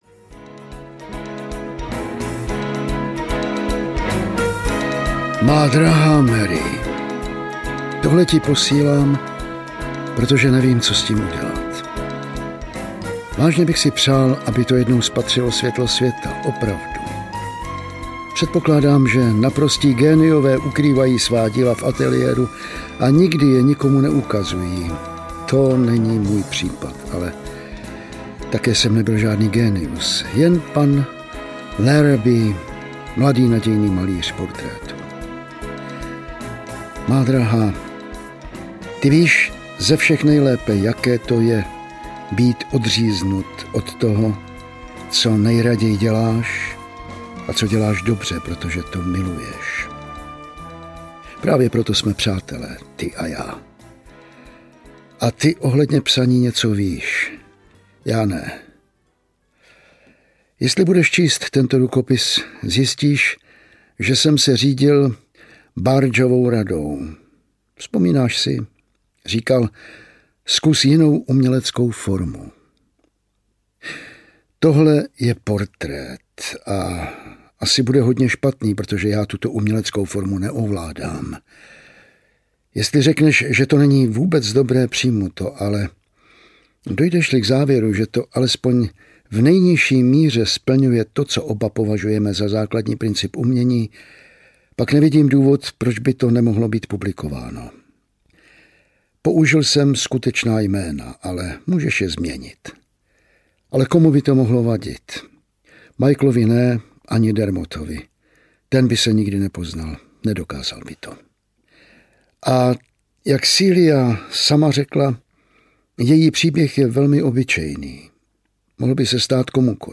Nedokončený portrét audiokniha
Ukázka z knihy
nedokonceny-portret-audiokniha